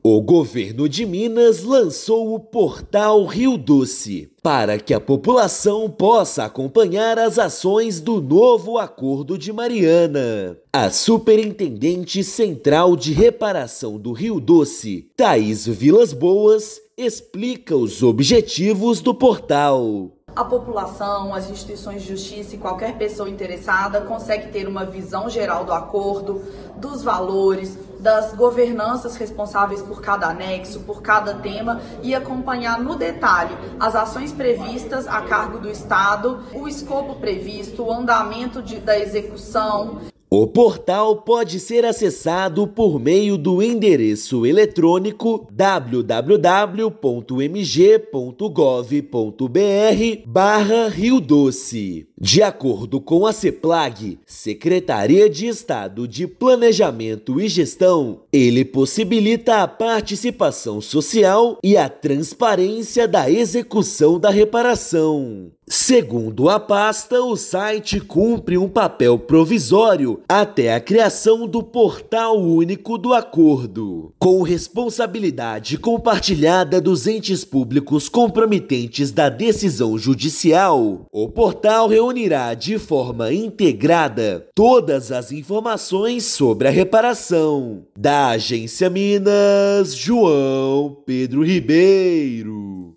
[RÁDIO] Governo de Minas lança portal do Novo Acordo de Mariana que garante transparência nas ações de reparação
Portal foi apresentado pela Seplag-MG e permite à sociedade acompanhar todas as ações previstas para o estado. Ouça matéria de rádio.